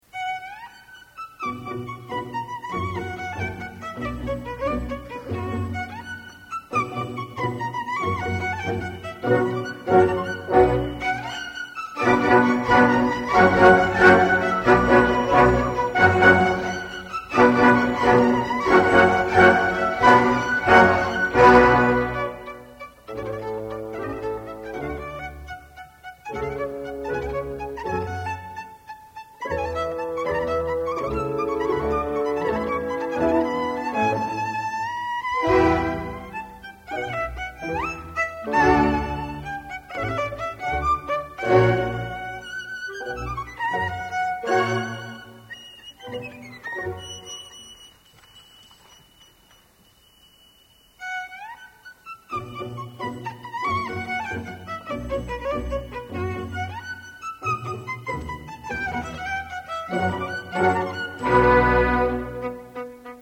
Concierto para violín.